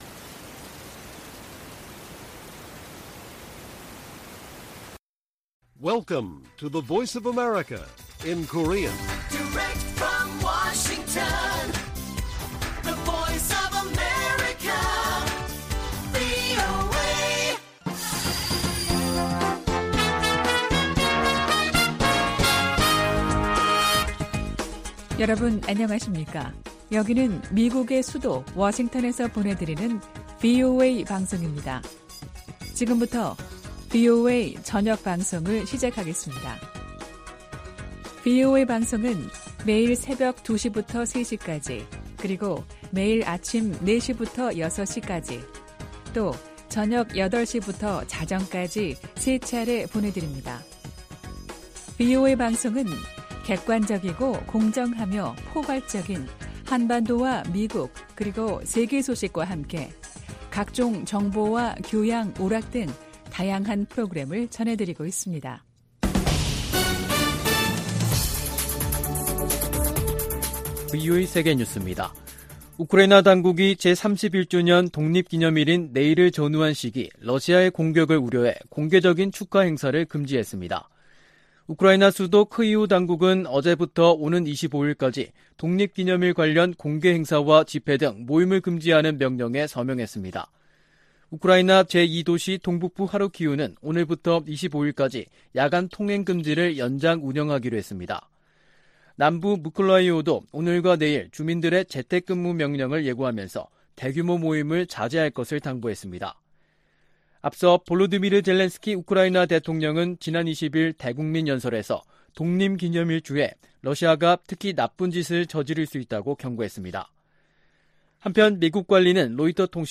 VOA 한국어 간판 뉴스 프로그램 '뉴스 투데이', 2022년 8월 23일 1부 방송입니다. 미 국무부는 한국 윤석열 정부의 ‘담대한 구상’이 미국 정부의 접근법과 일치한다며, 북한의 긍정적 반응을 촉구했습니다. 한국이 사상 처음으로 호주의 대규모 다국적 연합훈련인 피치블랙 훈련에 참가한다고 호주 국방부가 확인했습니다. 중국이 주한미군의 고고도 미사일 방어체계 즉 사드(THAAD)에 관해 한국에 압박을 이어가고 있습니다.